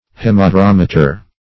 Search Result for " hemadrometer" : The Collaborative International Dictionary of English v.0.48: Hemadrometer \Hem`a*drom"e*ter\, Hemadromometer \Hem`a*dro*mom"e*ter\, n. [Hema- + Gr.